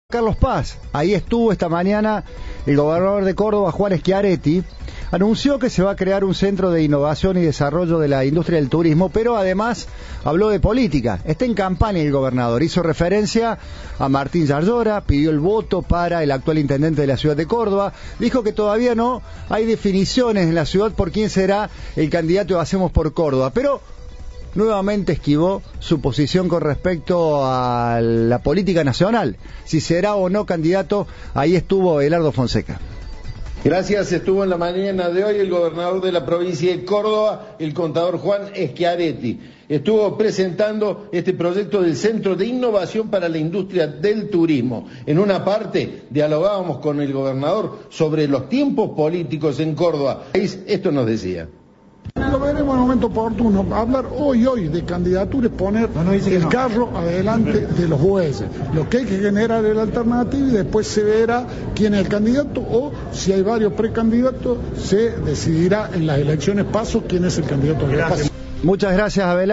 Síntesis de noticias